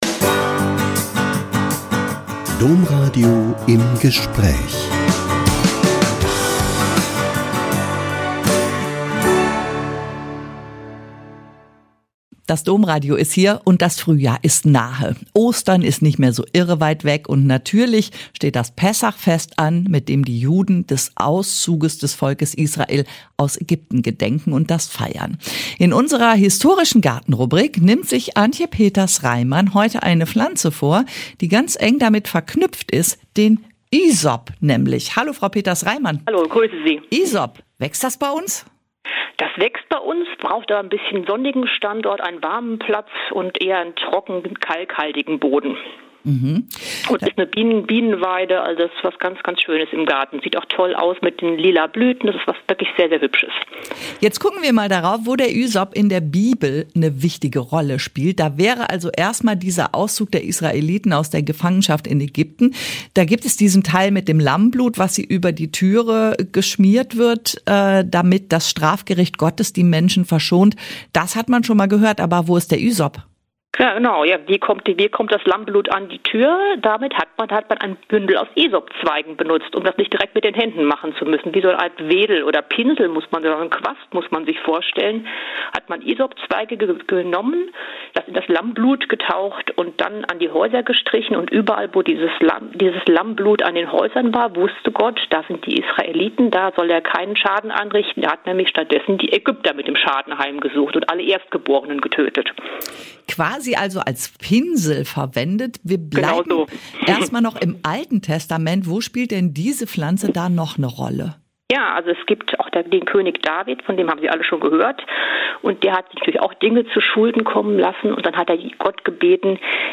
~ Im Gespräch Podcast